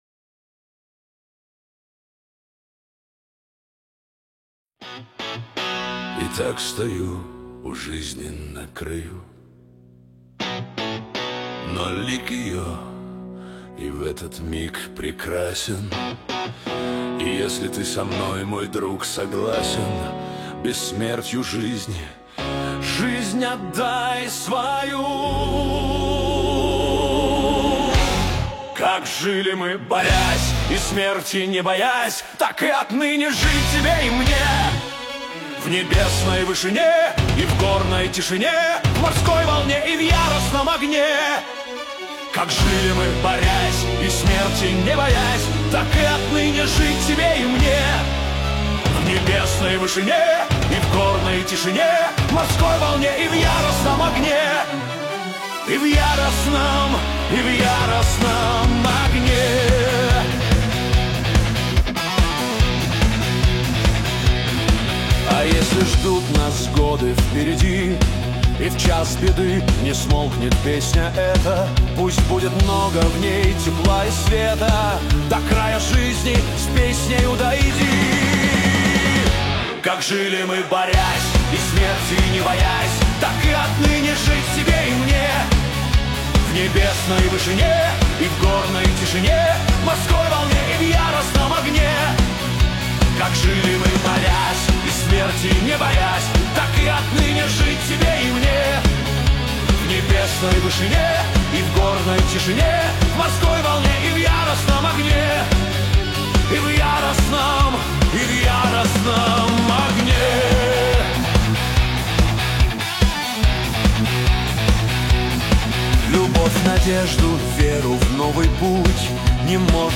Хард-рок кавер песни